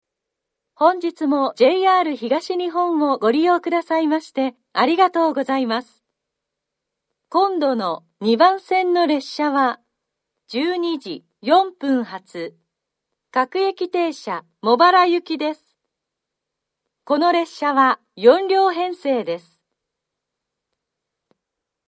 ２番線下り次発放送 12:04発各駅停車茂原行（４両）の放送です。